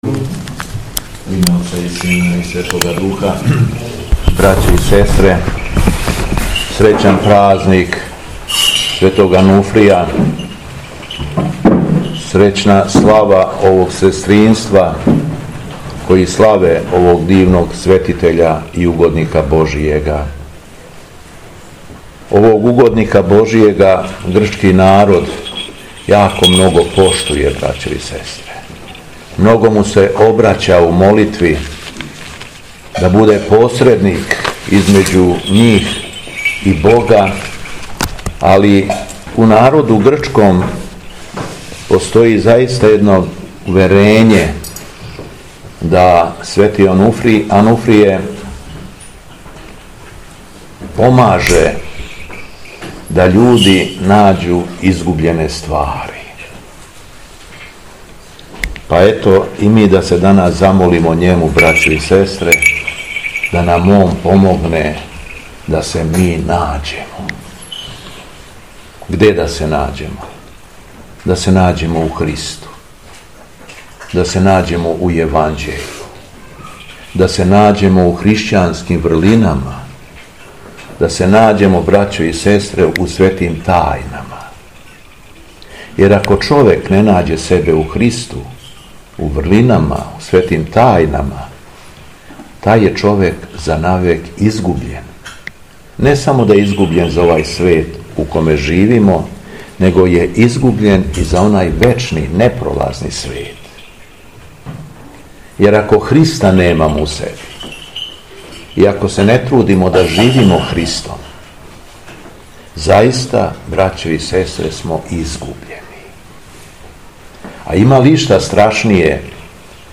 Беседа Његовог Преосвештенства Епископа шумадијског г. Јована
Беседећи, Епископ се верном народу обратио речима: